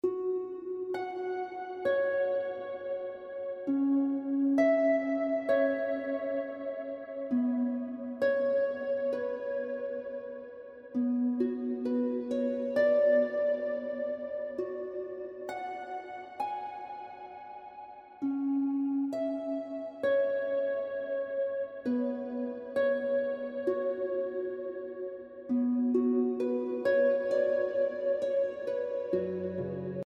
Heavenly Harp Music